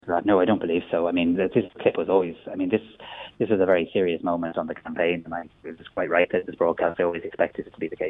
But speaking to South East Radio, the Taoiseach says he's not aware of any attempts from his party to stop the video from being posted.